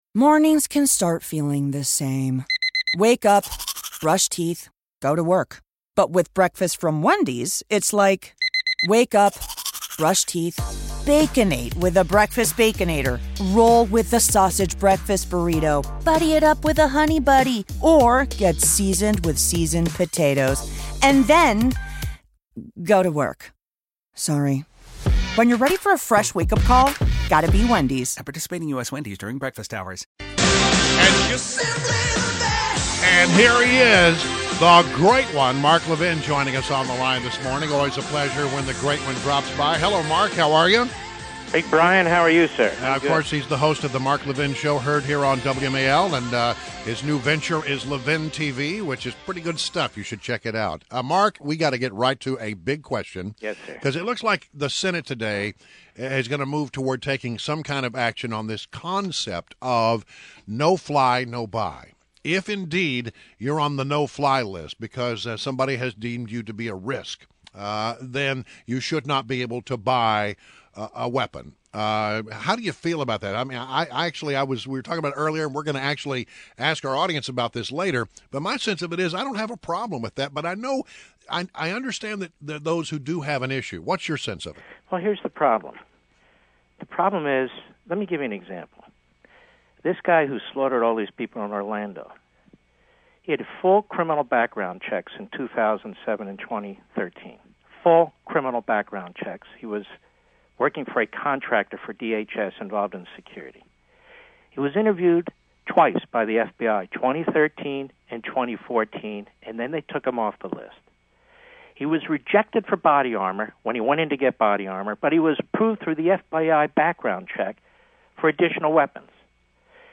WMAL Interview - MARK LEVIN - 06.16.16